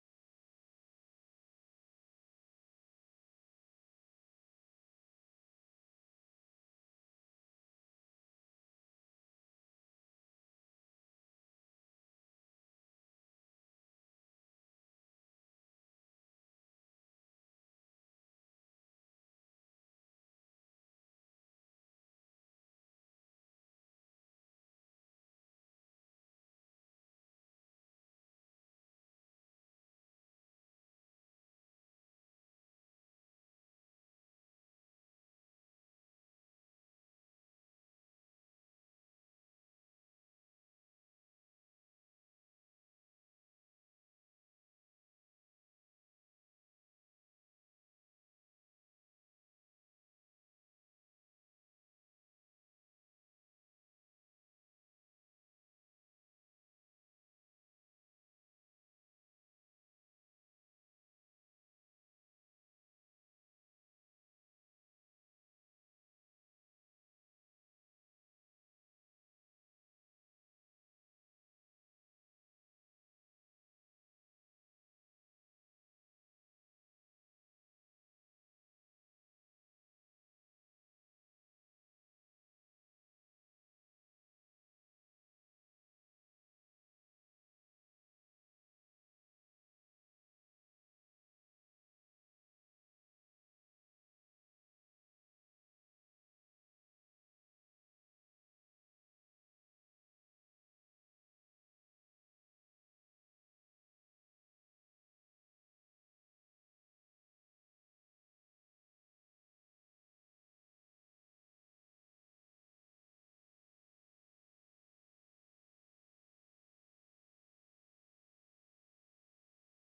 Morsels & Stories: I read from Edward Hays’ The Ethiopian Tattoo Shop.
Sermon: When people are sent to figure out who John is, John never quite answers the question asked.